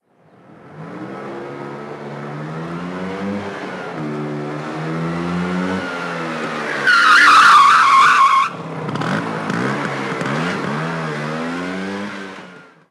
Moto marca Vespa frenazo 1
frenazo
motocicleta
Sonidos: Transportes